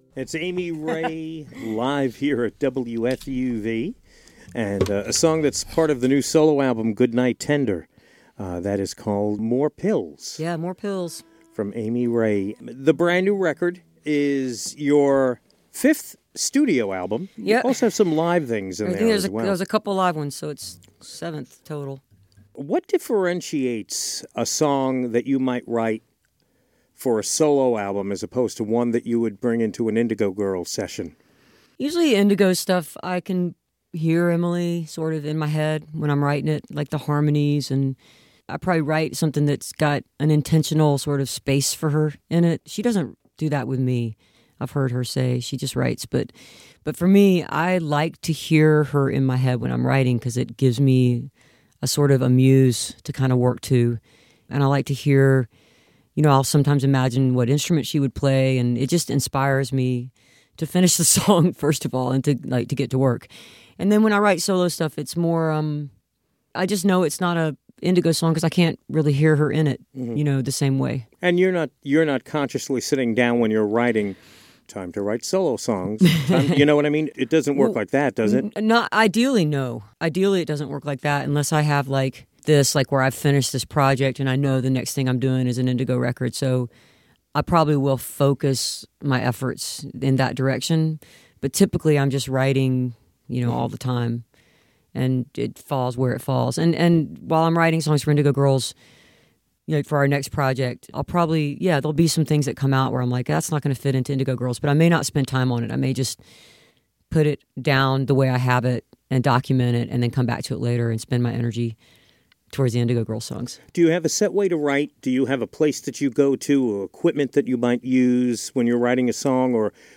lifeblood: bootlegs: 2013-12-06: studio a - wfuv (amy ray)
04. interview (5:17)